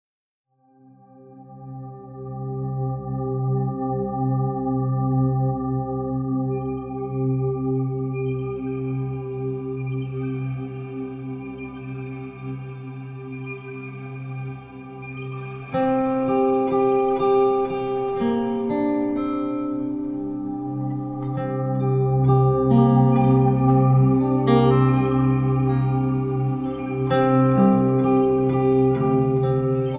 Acoustic, Electric Guitar and SuperCollider
Acoustic, Electric Guitar and Percussion